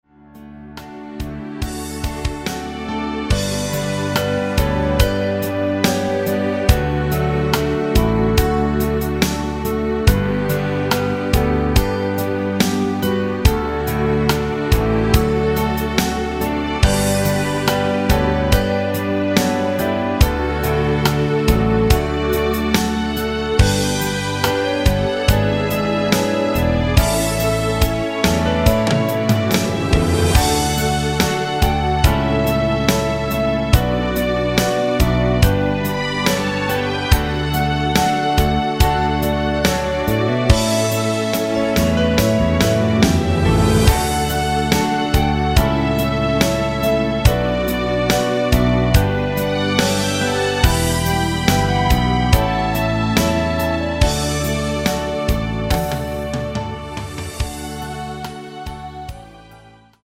원키 멜로디 포함된 MR 입니다.
멜로디 MR이라고 합니다.
앞부분30초, 뒷부분30초씩 편집해서 올려 드리고 있습니다.
중간에 음이 끈어지고 다시 나오는 이유는